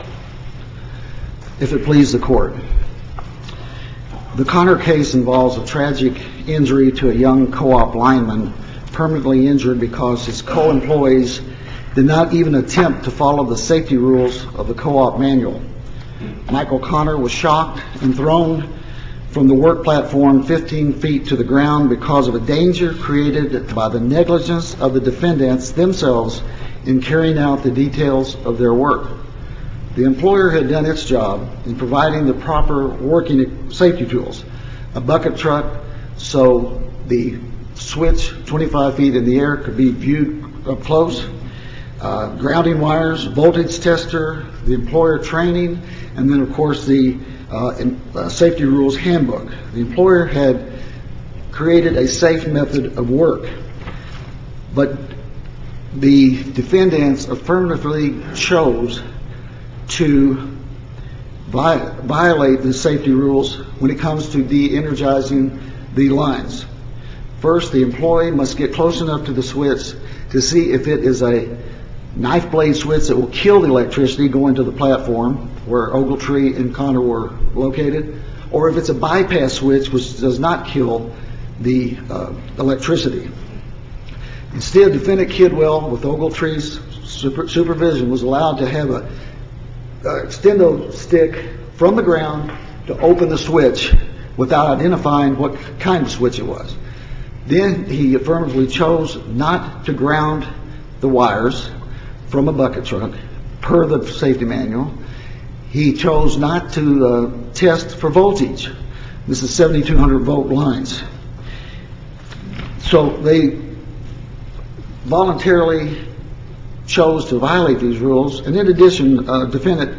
MP3 audio file of oral arguments in SC95995